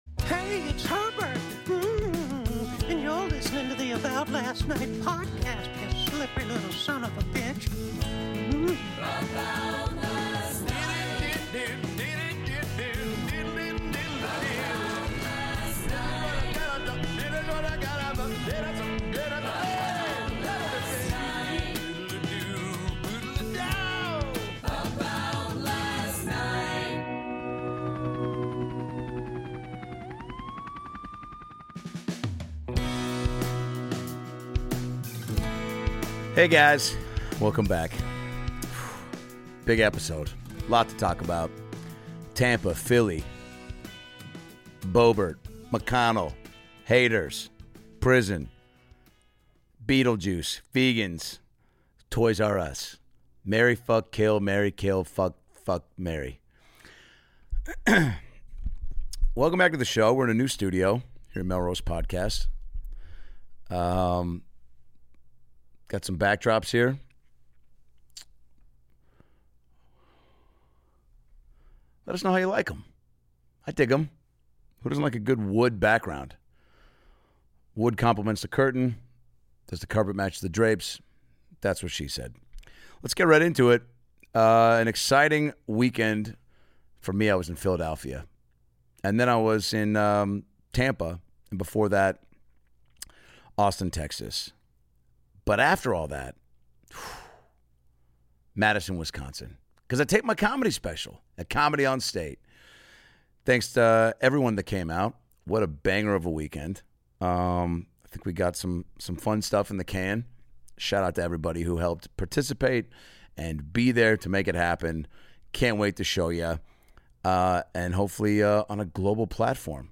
Adam Ray is back with a new solo episode where he talks about Lauren Boebert's crazy date, Aaron Rodger's strange recovery methods, Mitch McConnell freezing, the strange Toys R' Us commercials and more!